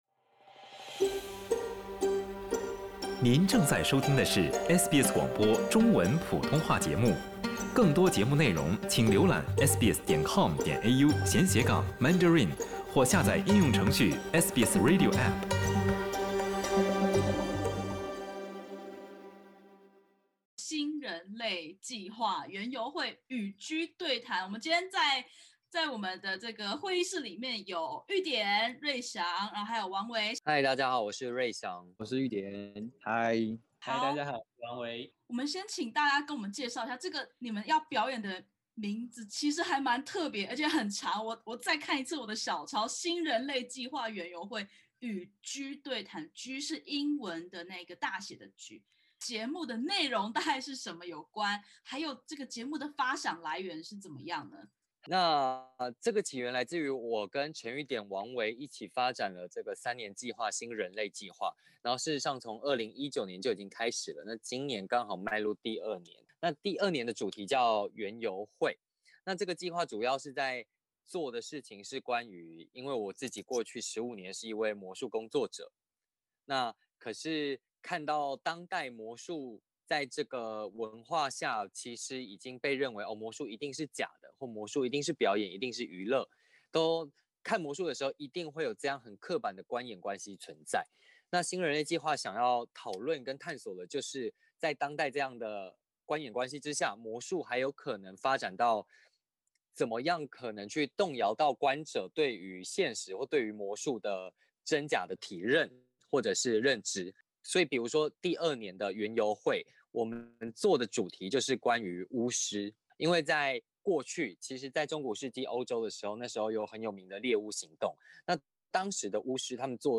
【专访】墨尔本艺穗节《新人类计划：园游会—与G对谈》